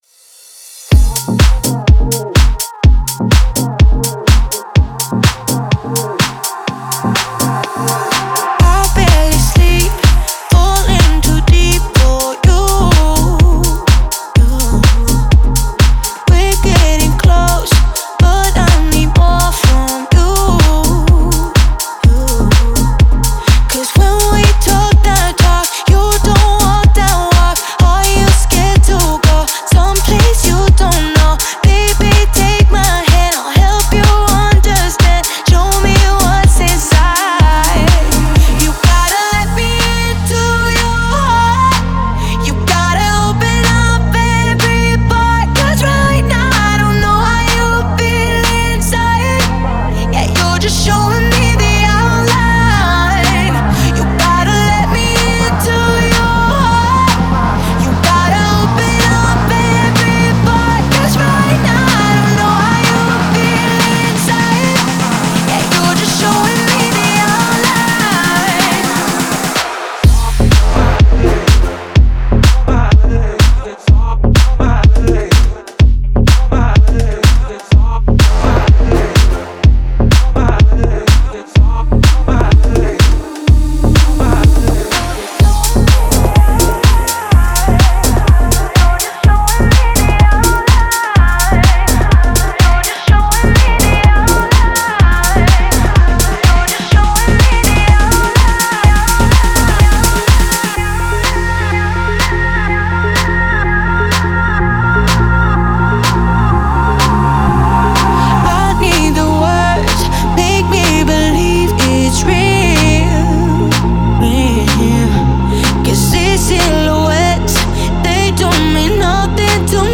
это энергичный трек в жанре хаус